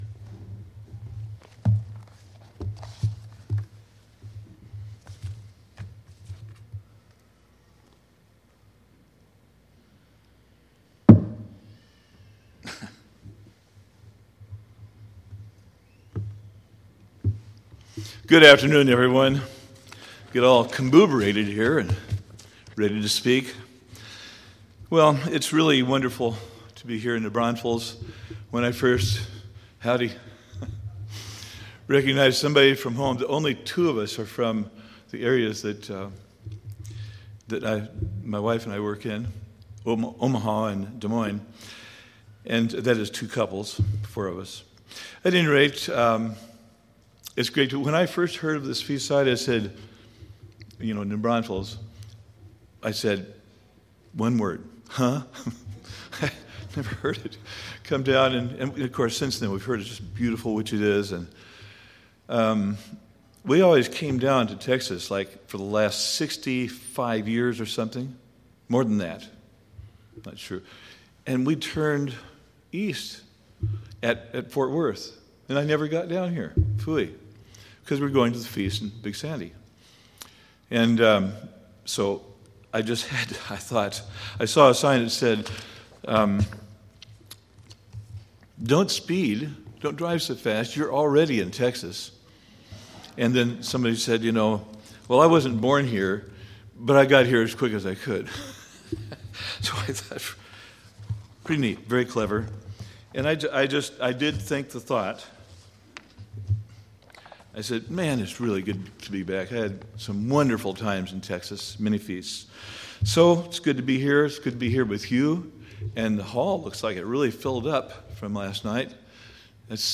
This sermon was given at the New Braunfels, Texas 2021 Feast site.